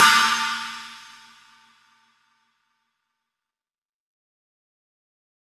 PCHINA.wav